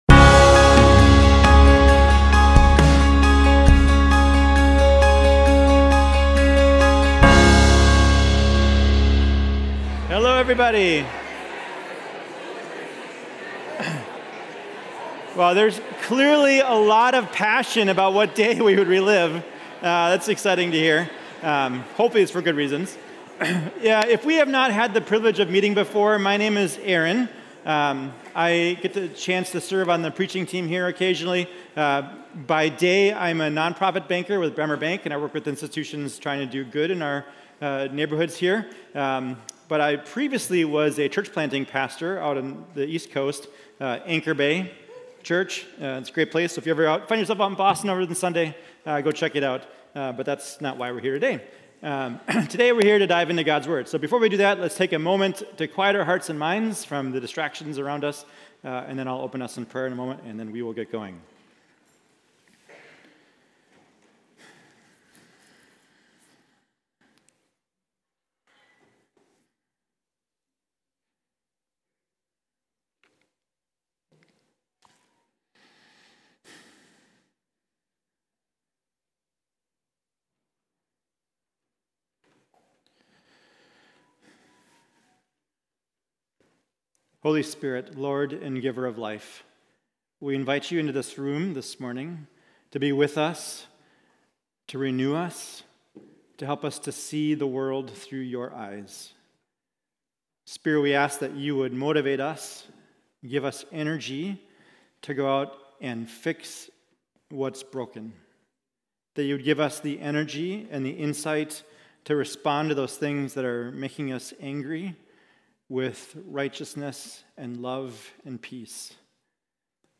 Mill City Church Sermons Shaped by Wisdom: Anger Nov 19 2024 | 00:32:28 1x 00:00 / 00:32:28 Subscribe Share RSS Feed Share Link Embed